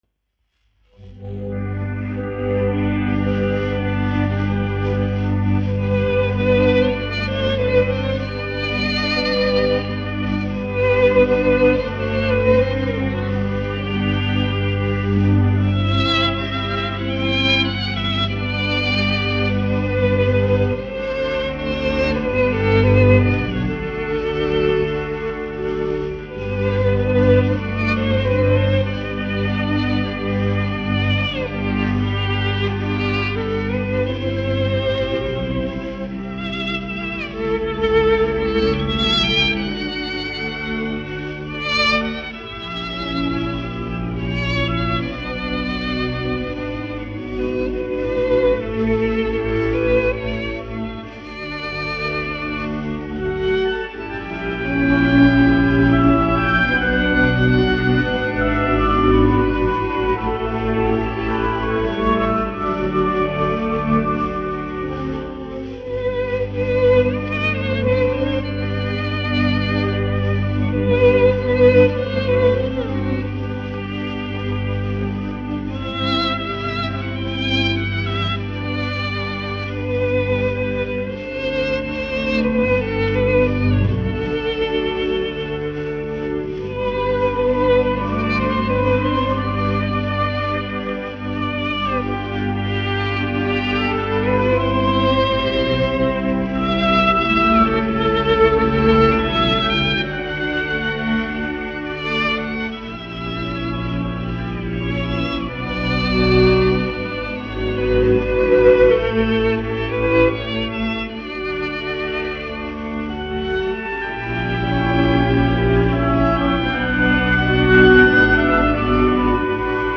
1 skpl. : analogs, 78 apgr/min, mono ; 25 cm
Vijole ar orķestri
Skaņuplate